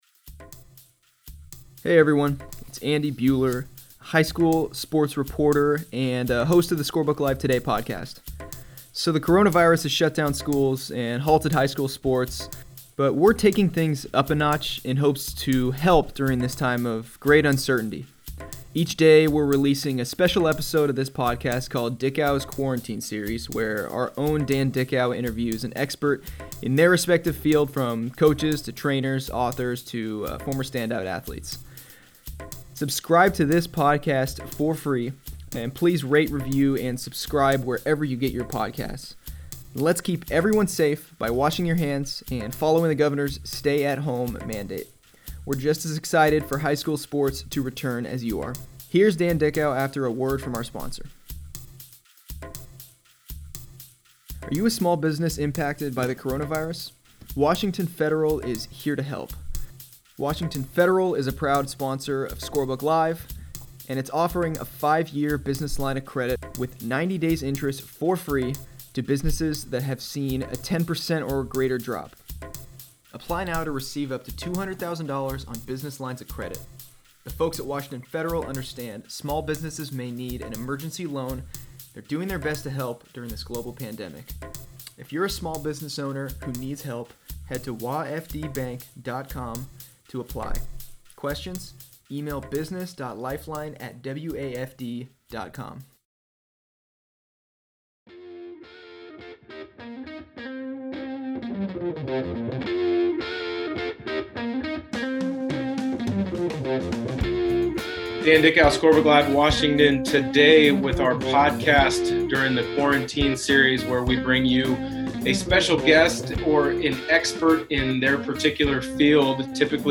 Each weekday, Dickau releases an interview with a wide range of experts in the world of sports.